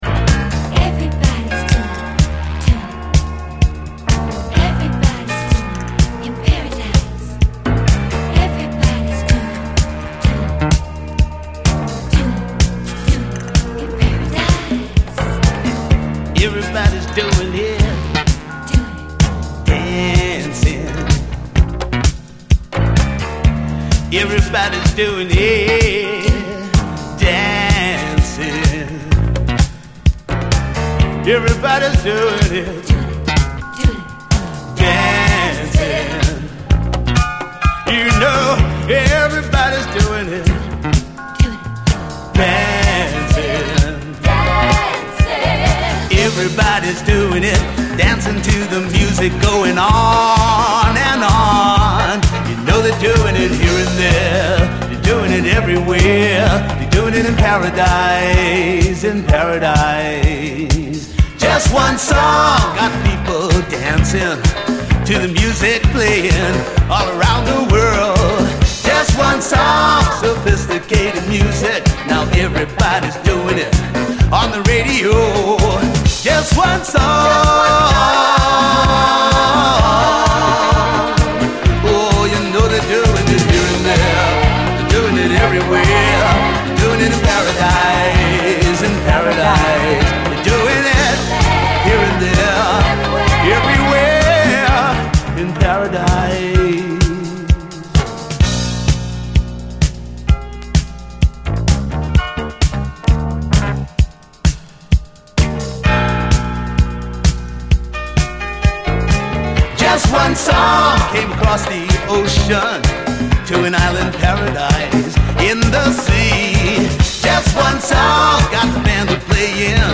SOUL, 70's～ SOUL, DISCO, SSW / AOR, ROCK
レア・ハワイアン・モダン・ブギー！